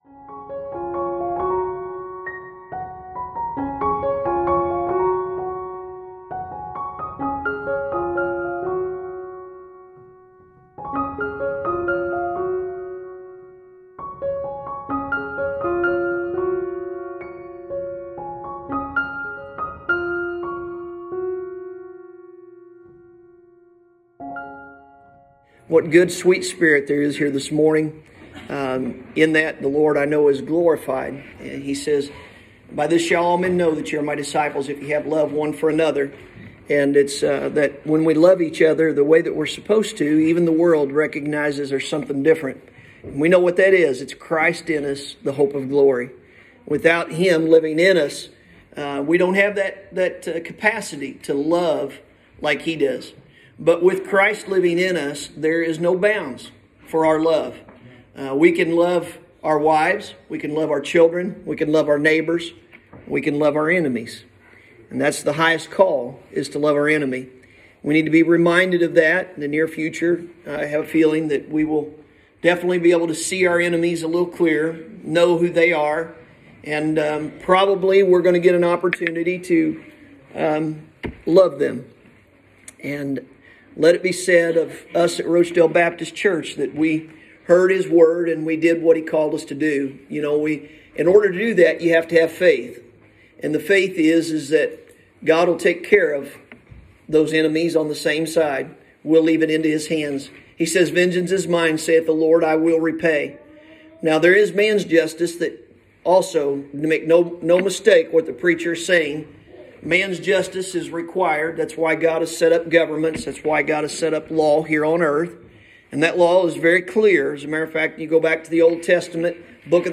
Sunday Morning – April 18th, 2021